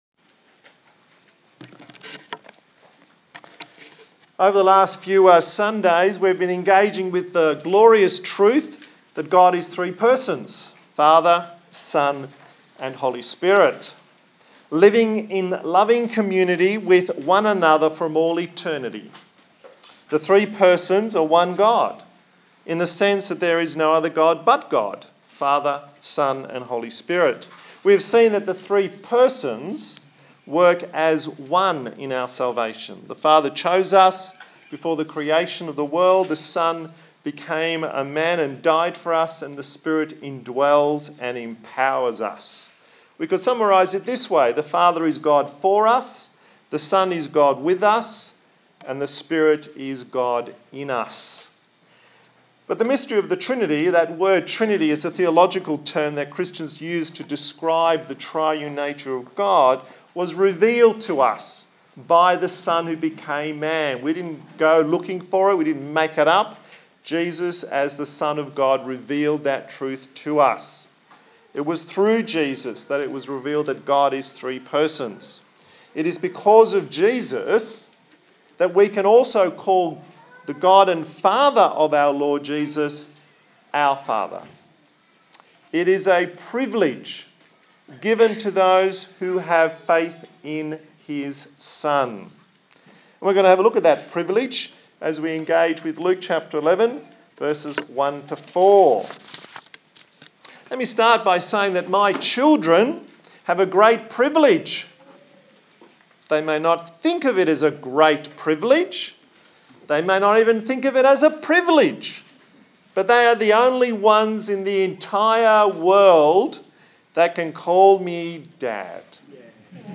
Luke 11:1-13 Service Type: Sunday Morning Praying is worshipping God.